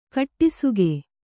♪ kaṭṭisuge